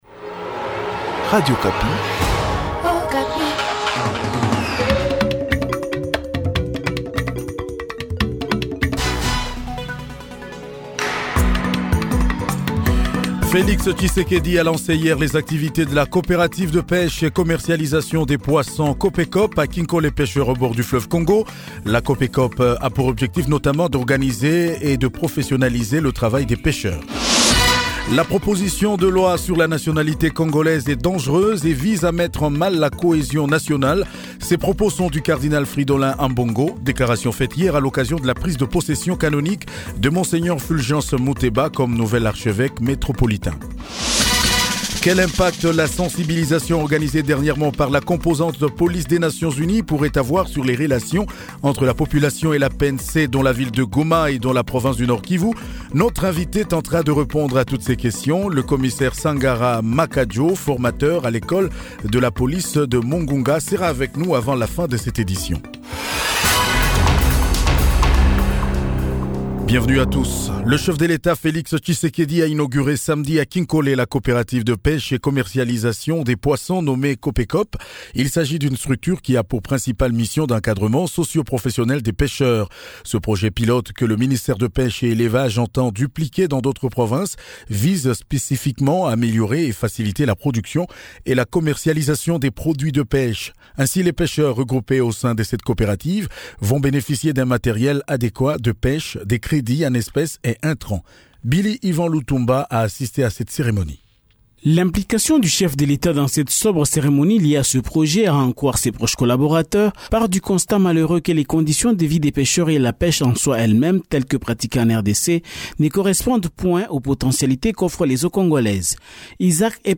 JOURNAL MIDI DU 11 JUILLET 2021